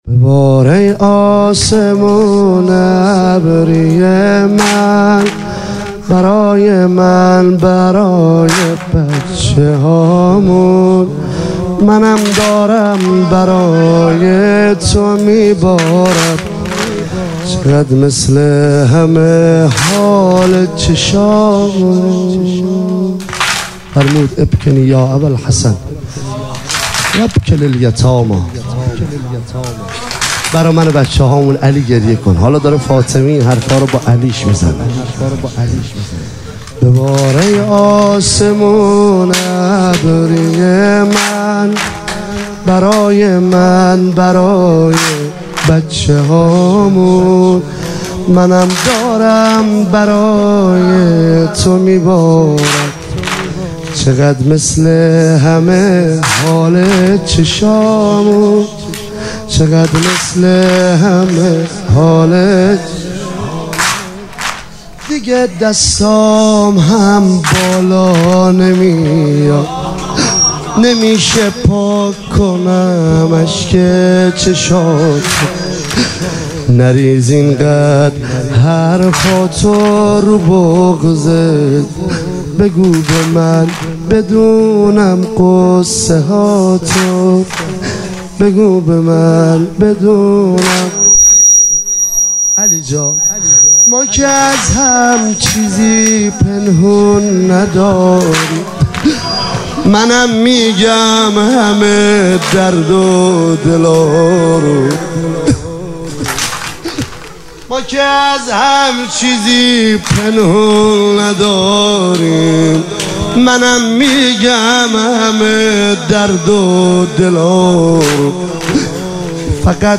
فاطمیه 97 - 21 بهمن - واحد - ببار ای آسمون ابری